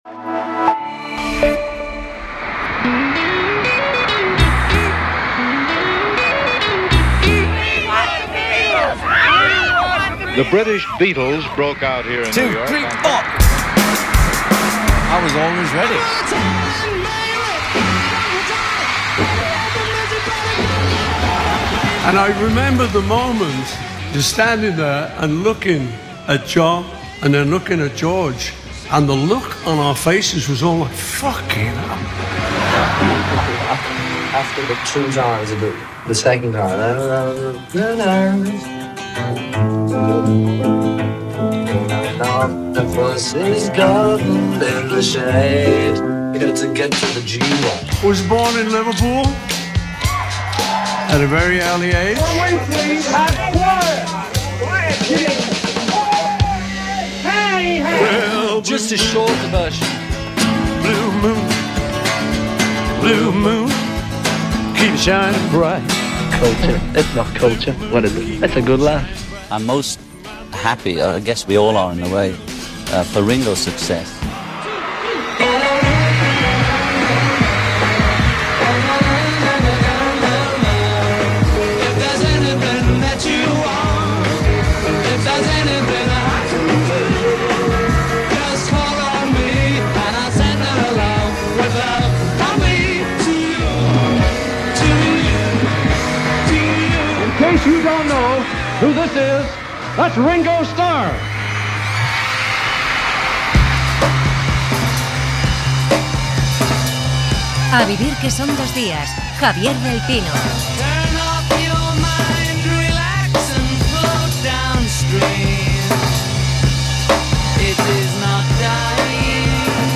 El periodista Javier del Pino ha entrevistado en A vivir que son dos días al ex-Beatle Ringo Starr, quien presenta su nuevo disco 'Long Long Road', un trabajo inspirado en la reflexión sobre su extensa trayectoria vital y musical a sus 85 años. Durante la conversación, el músico repasa sus inicios en Liverpool, recuerda anécdotas como su frustrado intento de emigrar a Estados Unidos y su relación con Paul McCartney, además de reivindicar el mensaje de “paz y amor” que ha marcado su carrera y su vida.